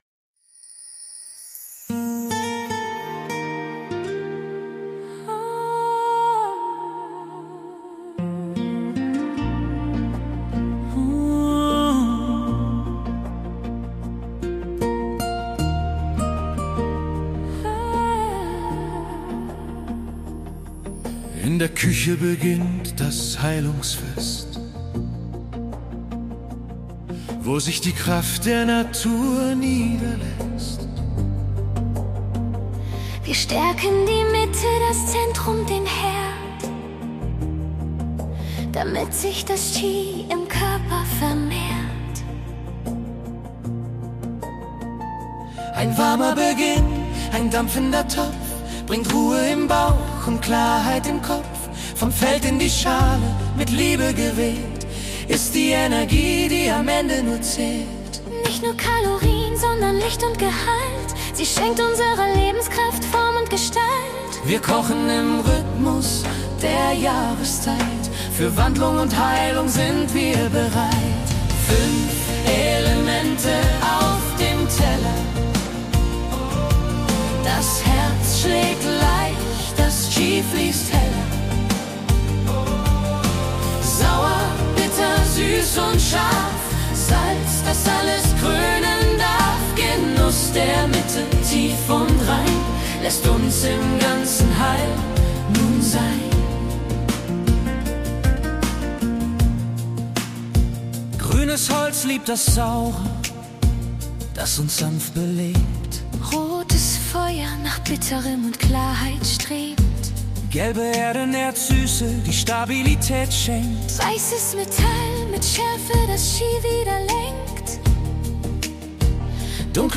Komposition mit Suno AI